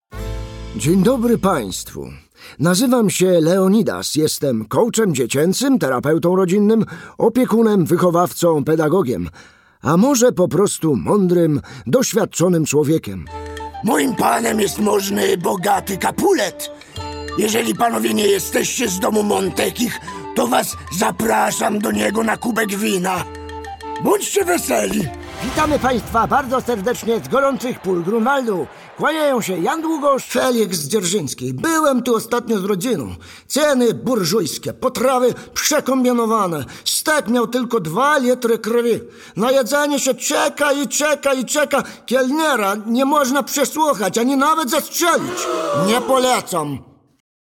Male 30-50 lat
Bajka